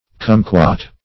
cumquat - definition of cumquat - synonyms, pronunciation, spelling from Free Dictionary
cumquat \cum"quat\, n. (Bot.)